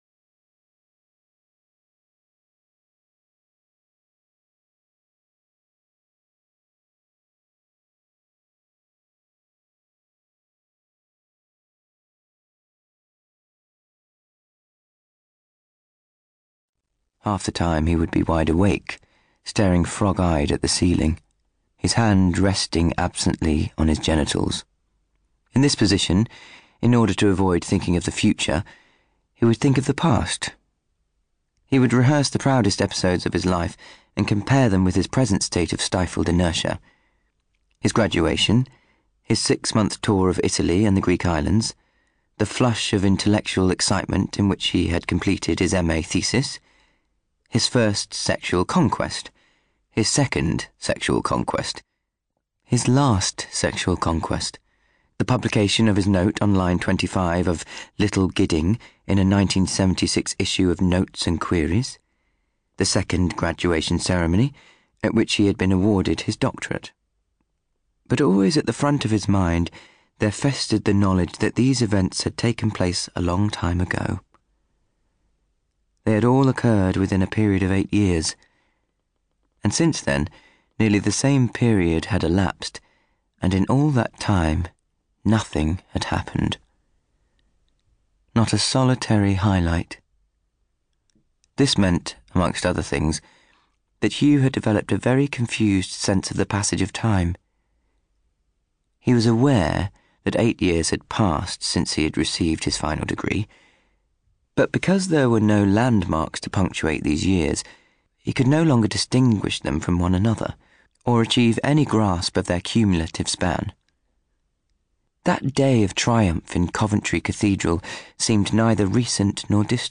30/40's Light Midlands/US,
Comedic/Expressive/Versatile